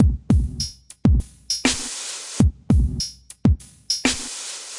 描述：古怪的idm鼓循环由我创造，结尾的数字表示节奏
Tag: 节拍 drumloop IDM